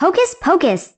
Voice Clips